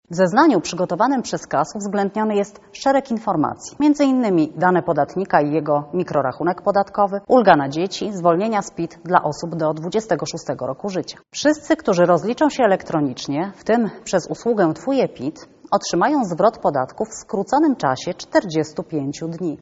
Więcej na temat tej opcji mówi szefowa Krajowej Administracji Skarbowej Magdalena Rzeczkowska: